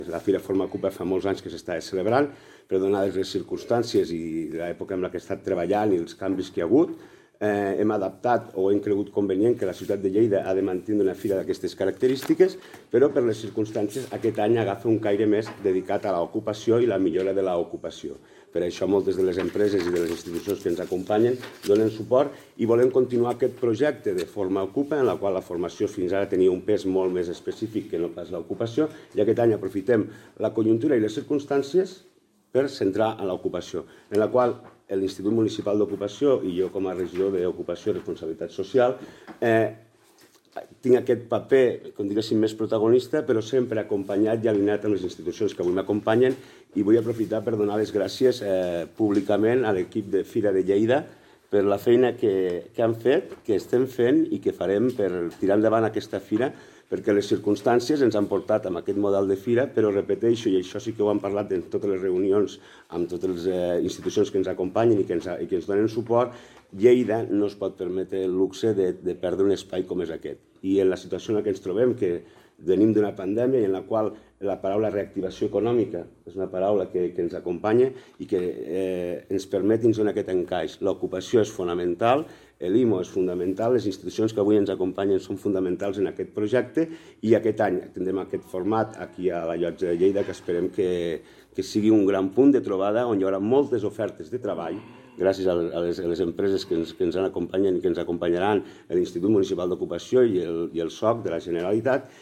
tall-de-veu-del-regidor-david-mele-sobre-el-salo-formaocupa-que-es-fara-el-4-de-maig-a-la-llotja